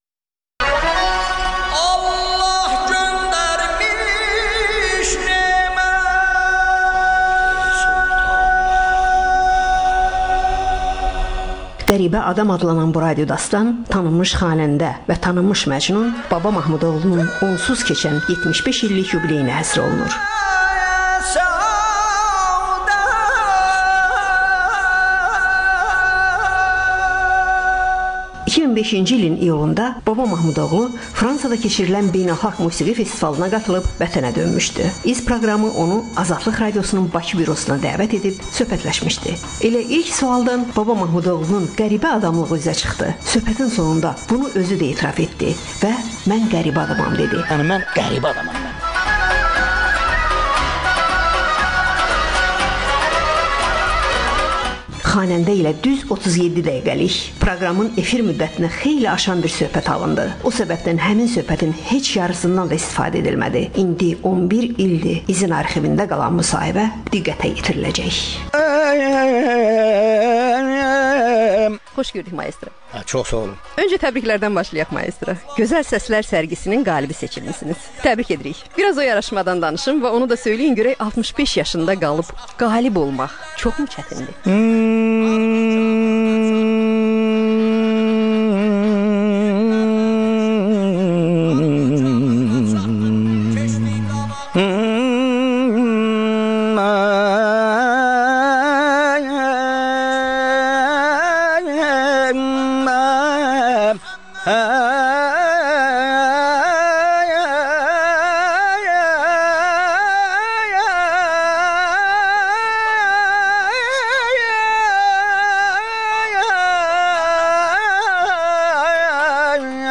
söhbət.